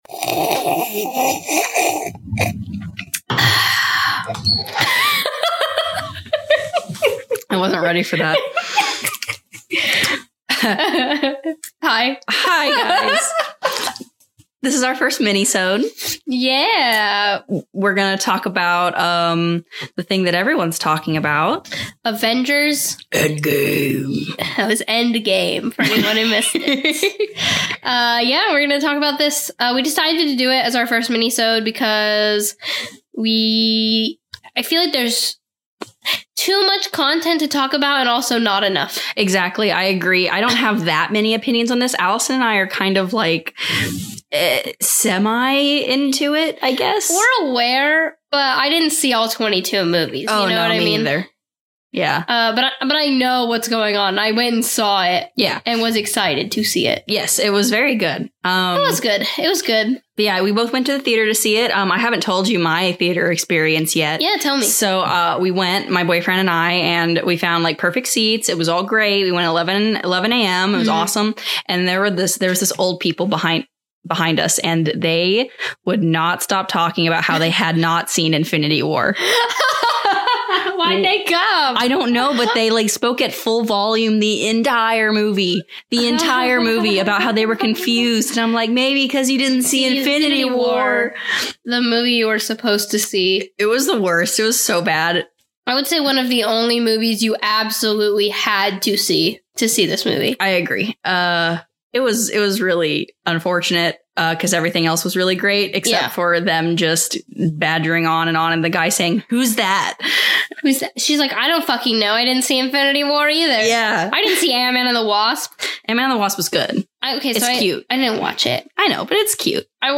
We discuss Avengers:Endgame with the limited knowledge we have. Some notes: There is a lot of background noise because it was storming heavily outside. Also be prepared for a loud slurping noise at the beginning.